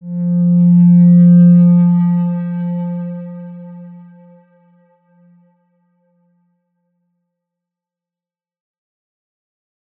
X_Windwistle-F2-ff.wav